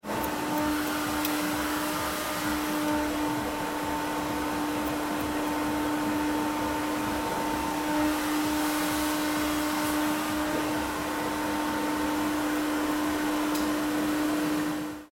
At the hairdressers – kuaför
A few days ago I went to cut my hair, and recognised the familiar sounds and scents. The keynote sound of hairdressers, the fön, huffed most of the time accompanied by the radio and small chatter of ladies having their nails done: